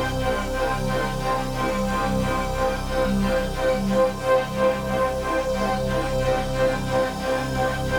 Index of /musicradar/dystopian-drone-samples/Tempo Loops/90bpm
DD_TempoDroneD_90-C.wav